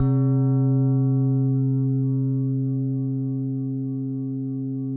WHINE  C1 -R.wav